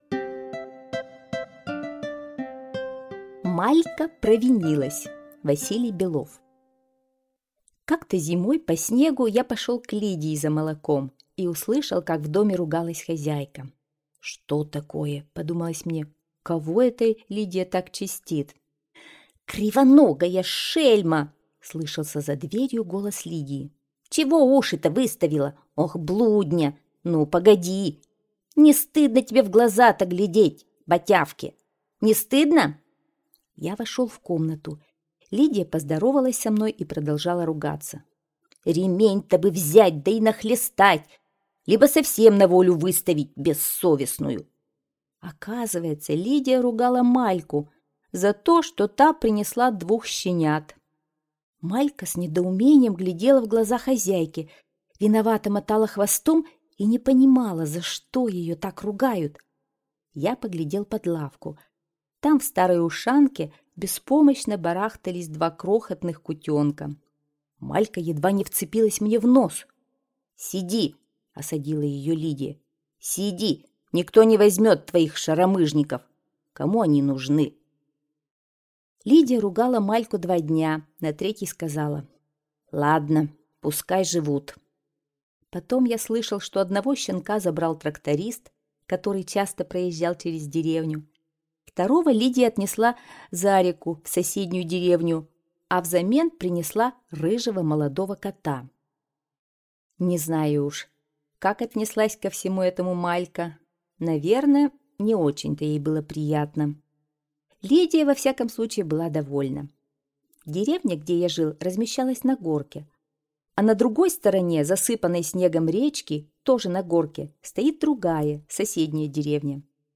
Аудиорассказ «Малька провинилась» – Белов В.И.